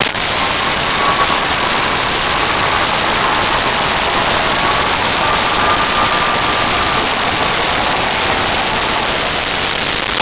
Large Crowd Applause 02
Large Crowd Applause 02.wav